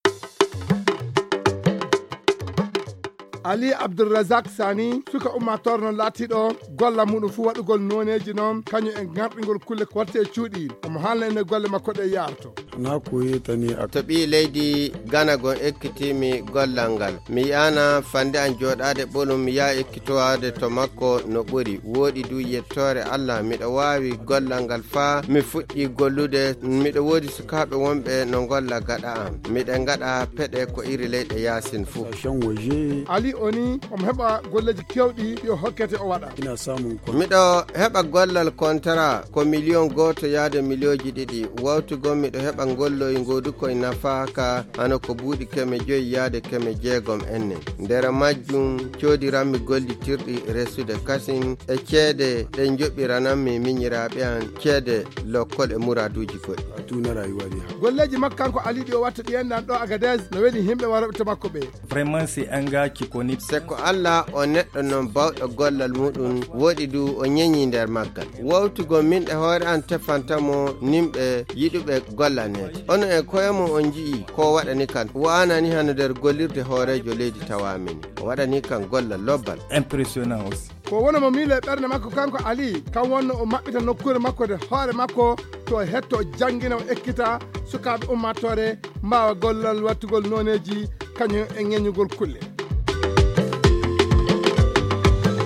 Le portrait en fulfuldé